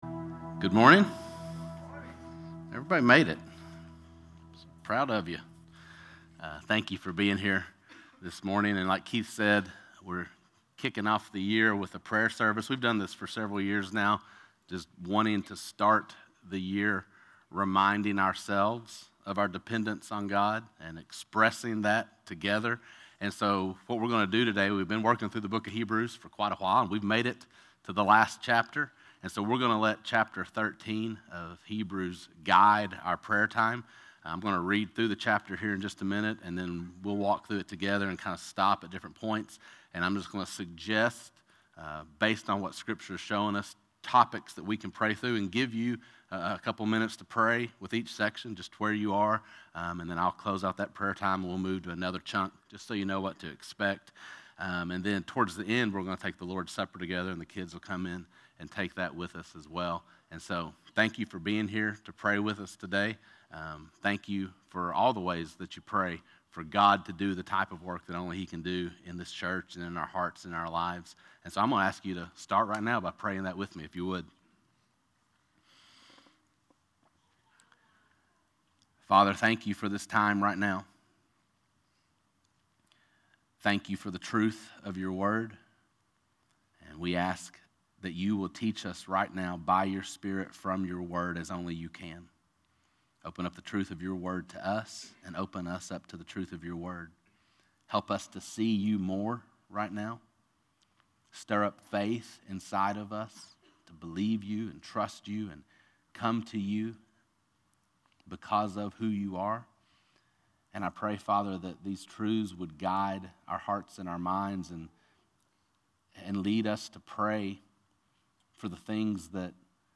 Prayer Service (Hebrews 13)